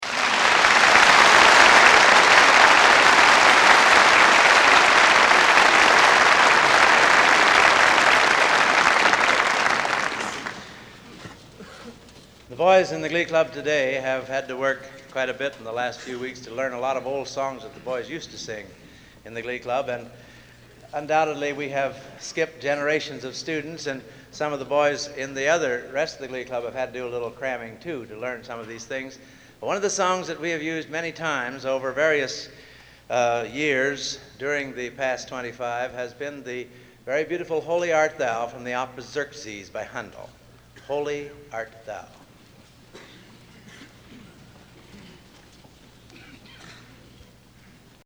Collection: Gala Anniversary Concert 1958
Location: West Lafayette, Indiana
Genre: | Type: Director intros, emceeing